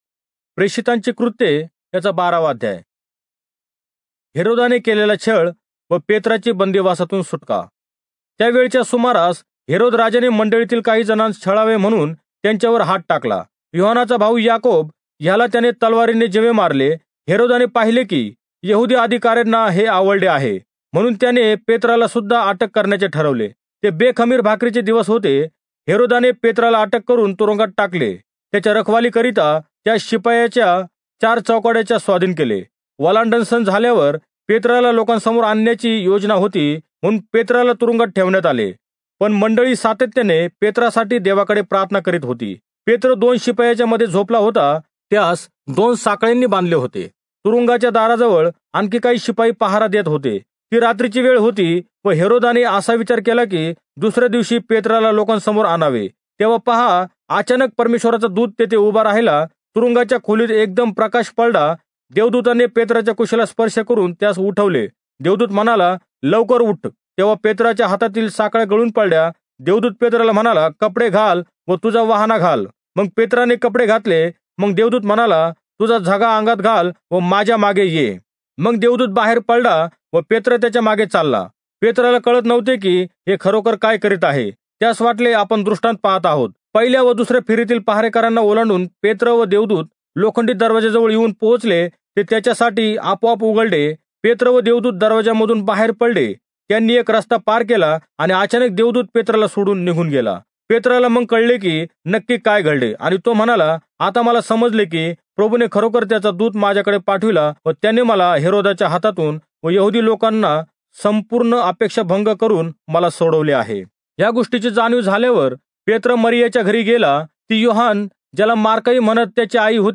Marathi Audio Bible - Acts 11 in Irvmr bible version